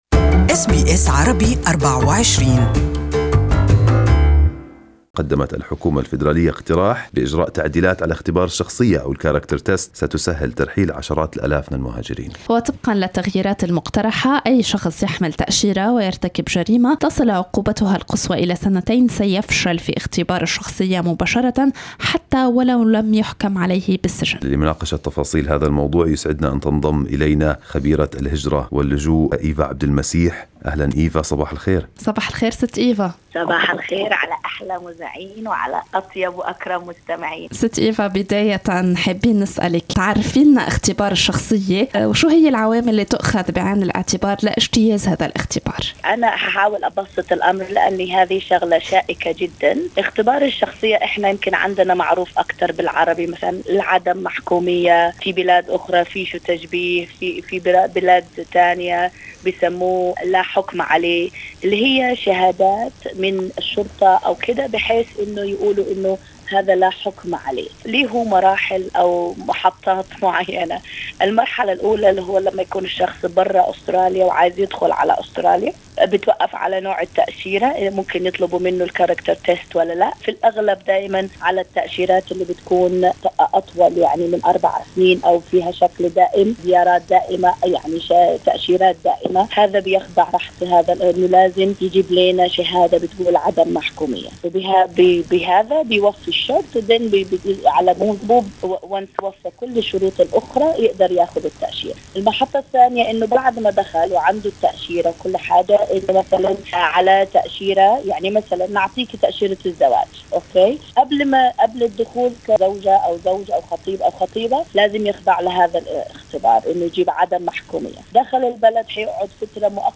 لمزيد من التفاصيل استمعوا إلى اللقاء الكامل